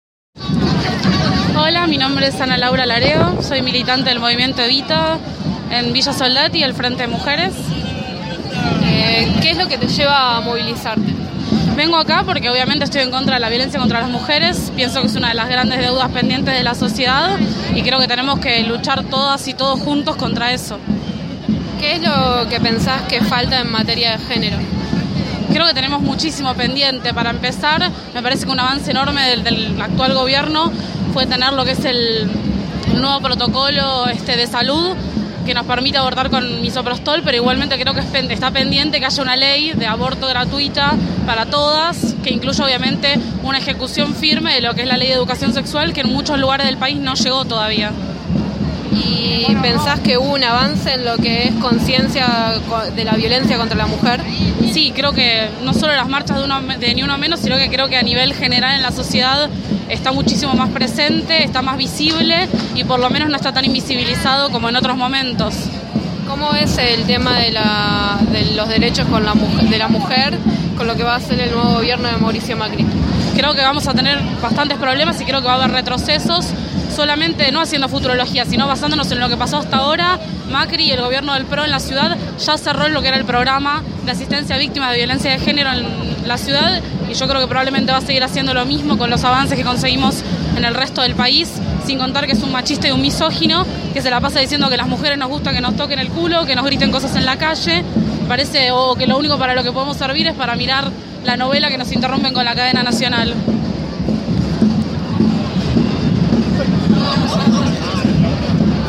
Es por ello que el Día Internacional de la Eliminación de la Violencia contra la Mujer se conmemoró frente al Congreso, en el que se dijo una vez más “Ni una menos”.
Diferentes corrientes políticas se hicieron presente en la Plaza de los Dos Congresos para manifestar su repudio a la violencia sexista y señalar lo que todavía falta conquistar.